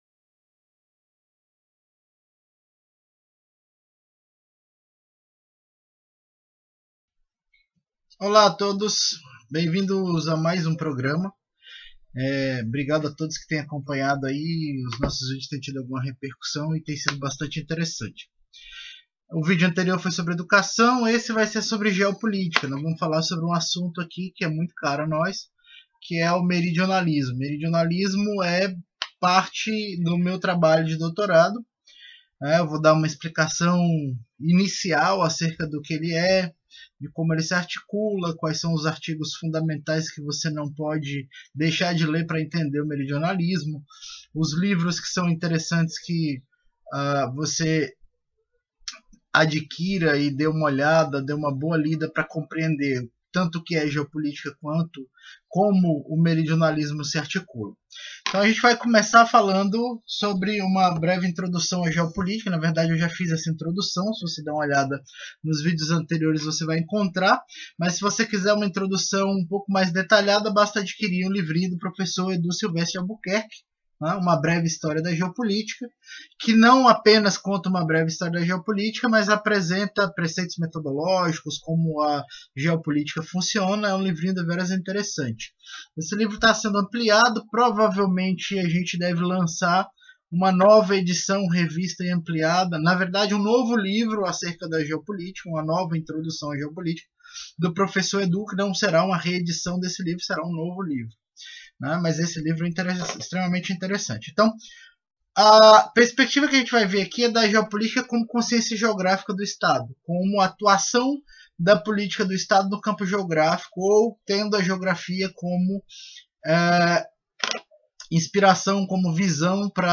Palestra ministrada no CEM